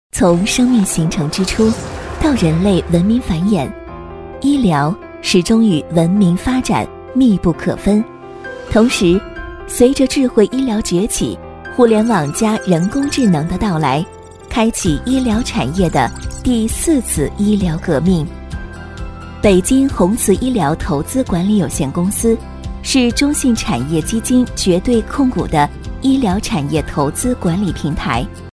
旁白-女19-医疗企业宣传片.mp3